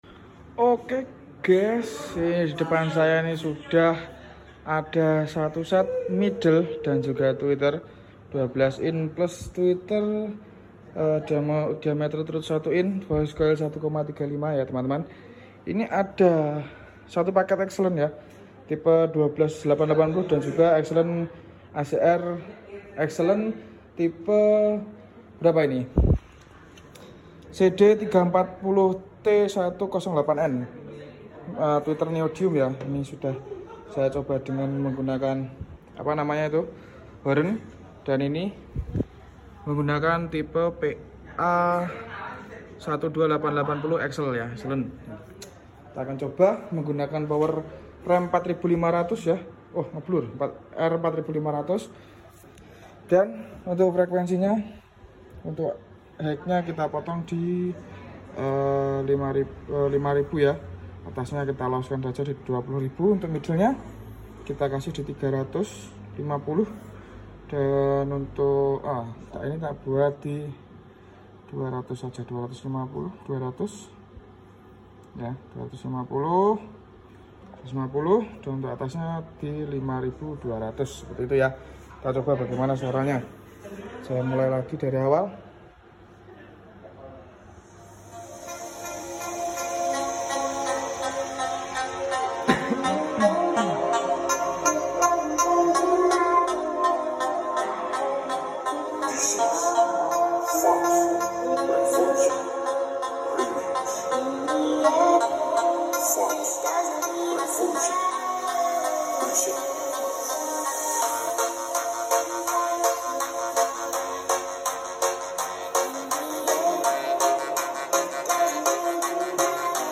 Satu set Speaker midle dan tweeter dari acr exelent tipe 12880 dan CD340T1-08N.